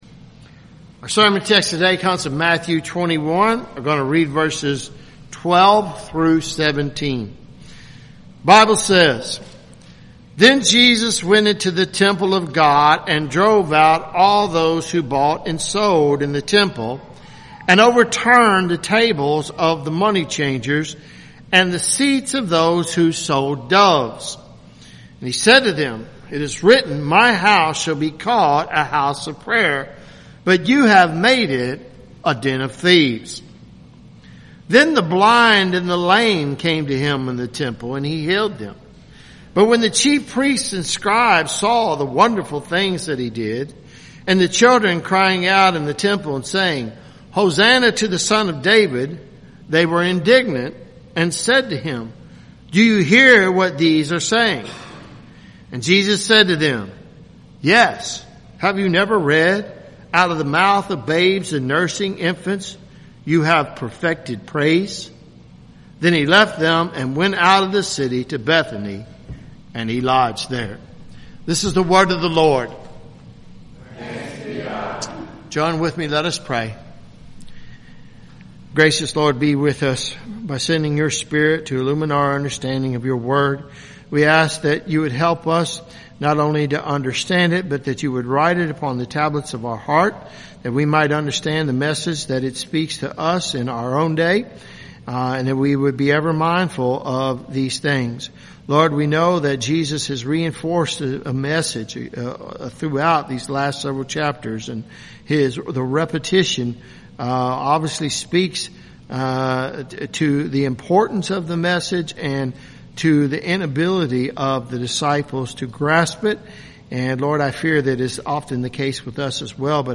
at Christ Covenant Presbyterian Church, Lexington, Ky.